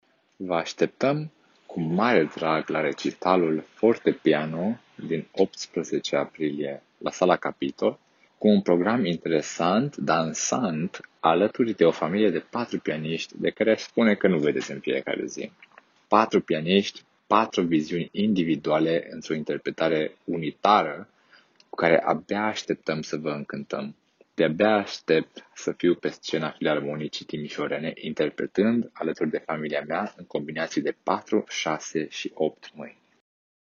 interviuri, pentru Radio Timișoara, cu pianiștii